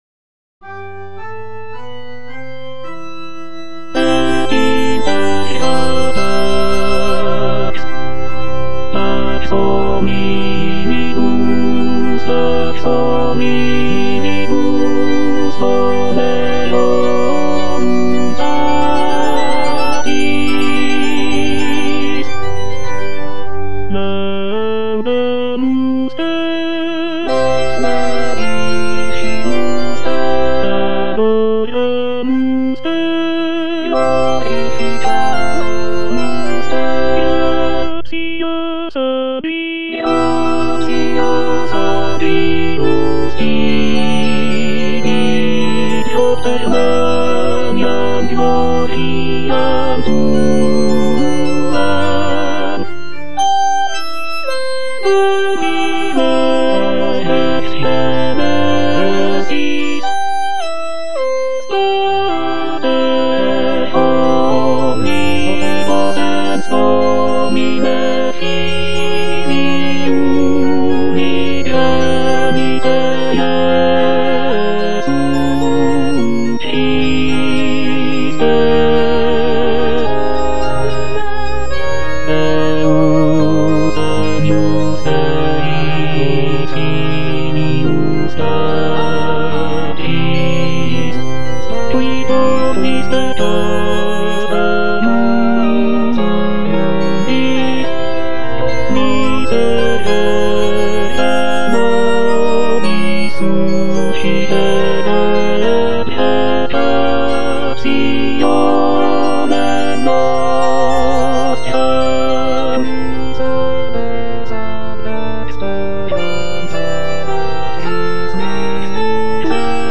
Tenor (Emphasised voice and other voices) Ads stop